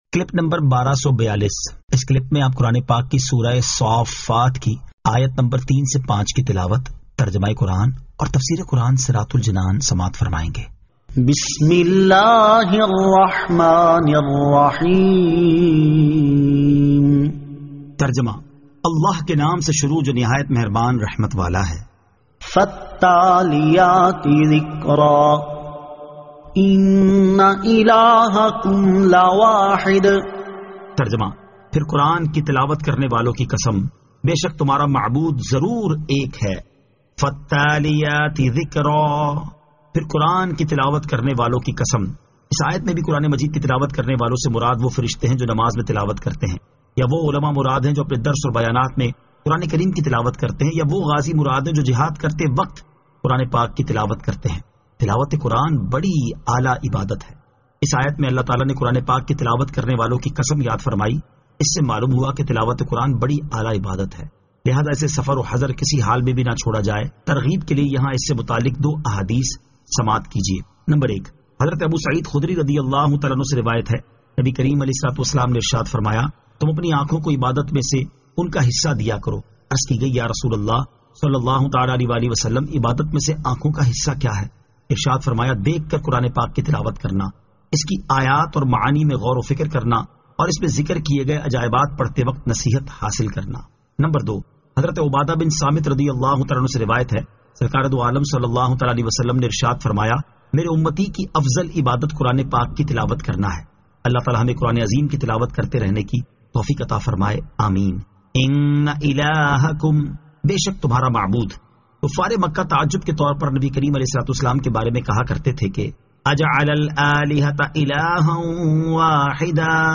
Surah As-Saaffat 03 To 05 Tilawat , Tarjama , Tafseer